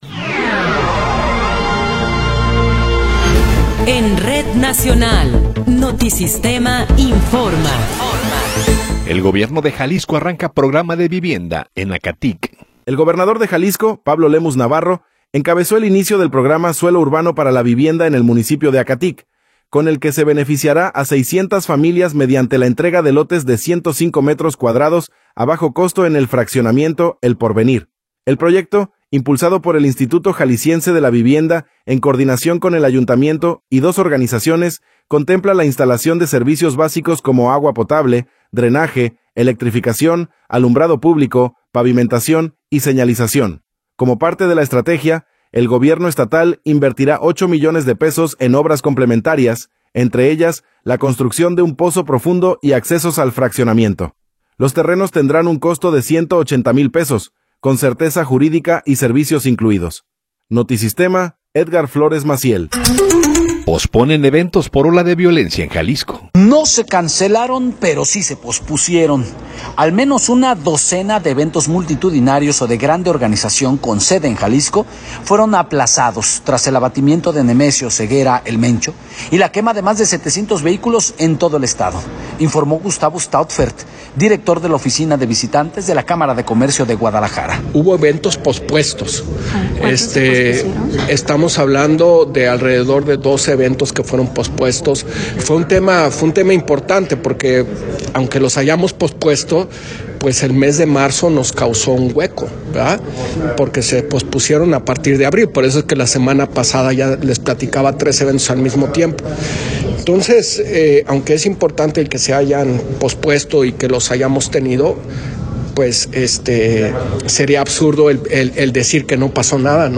Noticiero 17 hrs. – 21 de Abril de 2026
Resumen informativo Notisistema, la mejor y más completa información cada hora en la hora.